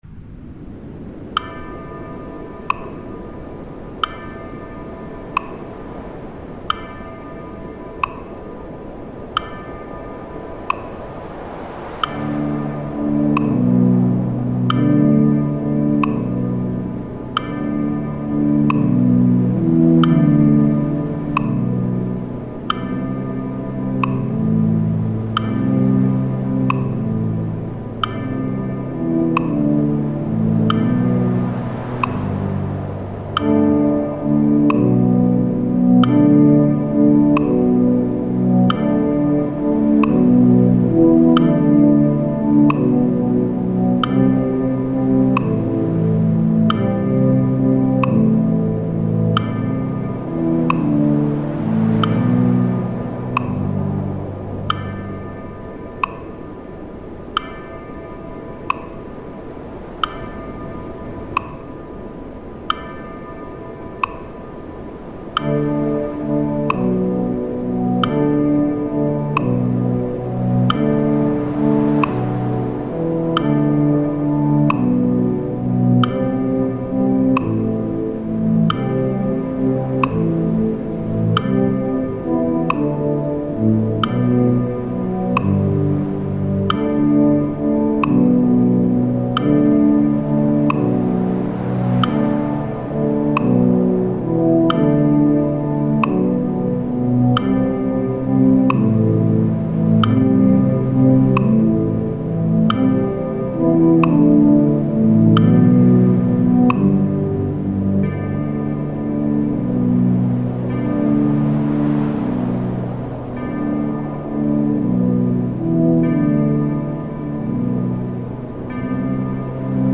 Music / Game Music
creepy